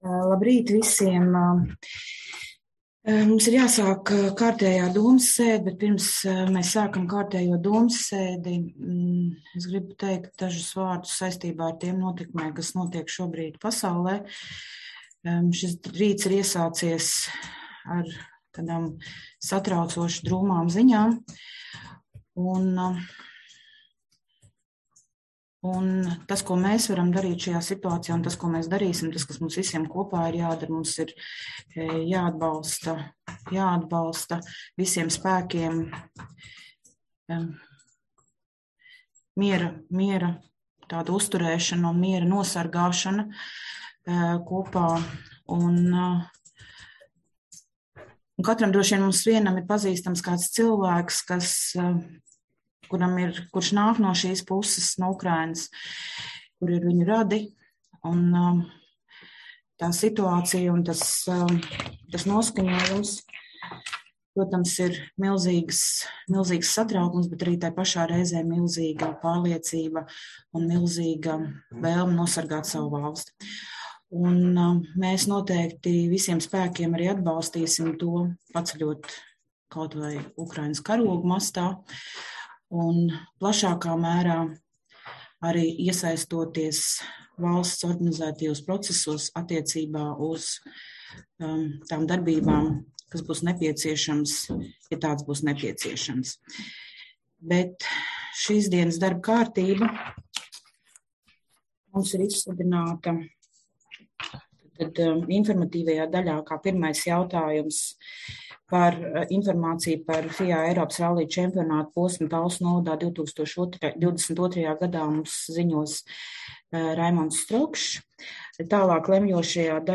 Balss ātrums Publicēts: 24.02.2022. Protokola tēma Domes sēde Protokola gads 2022 Lejupielādēt: 5.